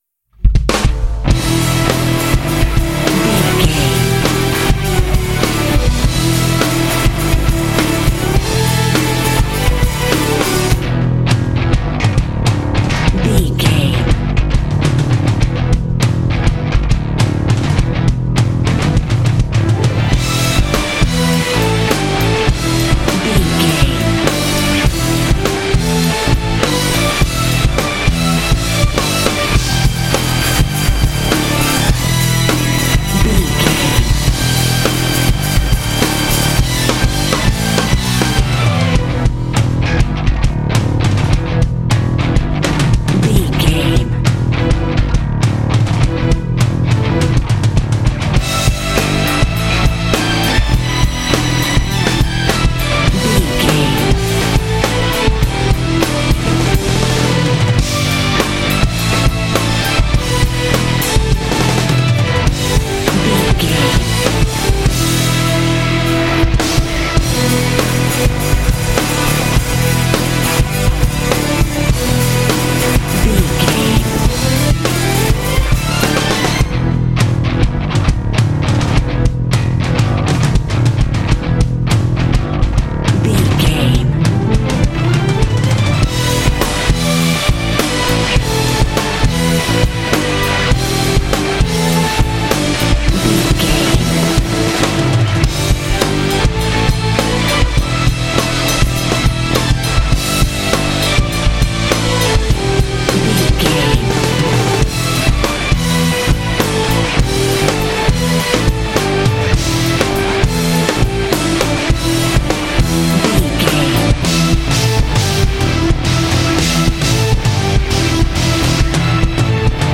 Aeolian/Minor
powerful
energetic
heavy
bass guitar
electric guitar
drums
strings
heavy metal
symphonic rock